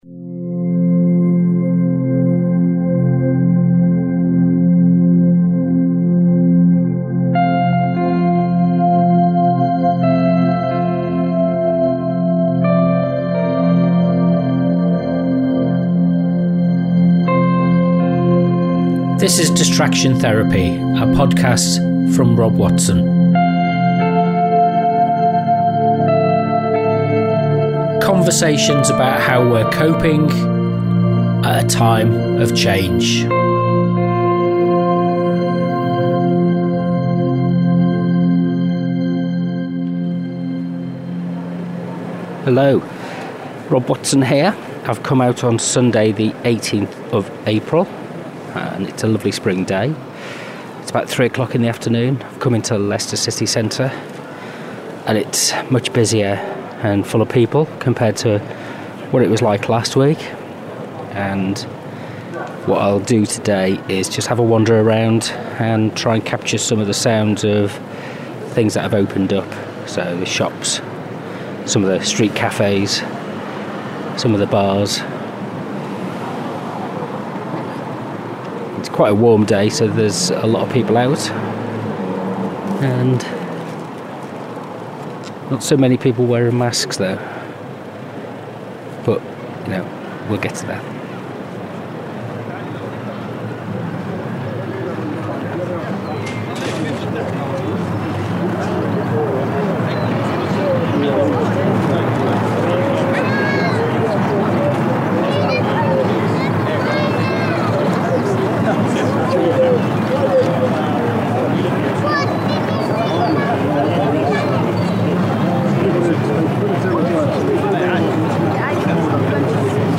Distraction Therapy Podcast 029 – Leicester Soundwalk – Travels in Metamodernity
Today I went for a stroll in Leicester city centre and visited a couple of shops, bars and cafés to get a sense of how people are responding to the easing of the lockdown. Seeing people back in the shops got me thinking about what our culture is and how our sense of identity is not strongly understood or defined, except in terms of brands, cars and beer.